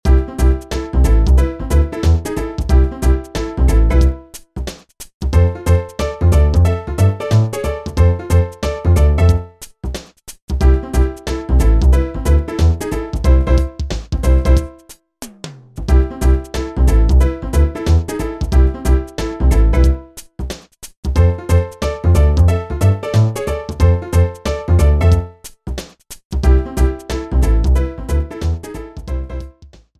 Shortened, applied fade-out, and converted to oga